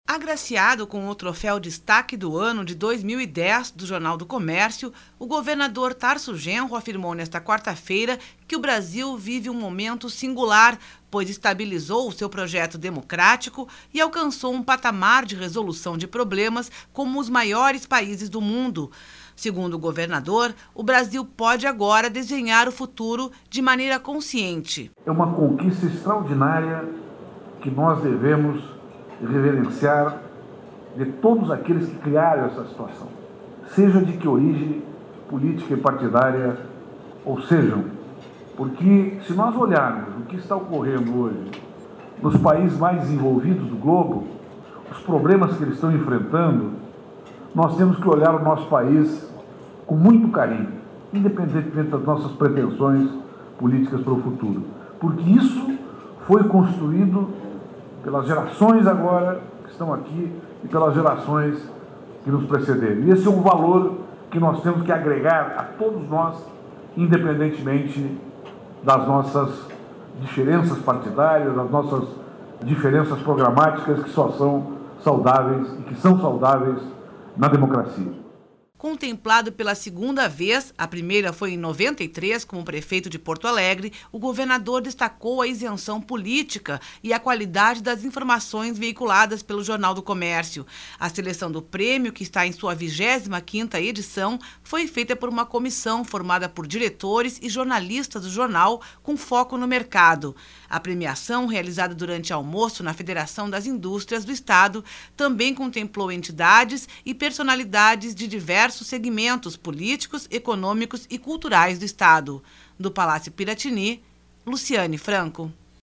Agraciado com o troféu Destaque do Ano de 2010 do Jornal do Comércio, o governador Tarso Genro afirmou nesta quarta-feira (25) que o Brasil vive um momento singular, pois estabilizou seu projeto democrático e alcançou um patamar de resolução de problemas